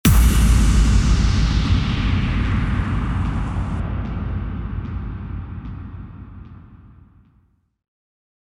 FX-1848-IMPACT
FX-1848-IMPACT.mp3